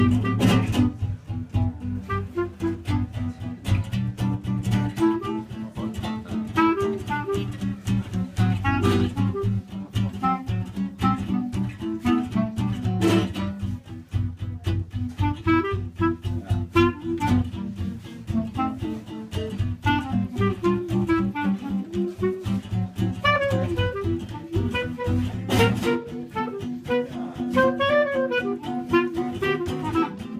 clarinette
guitare
contrebasse.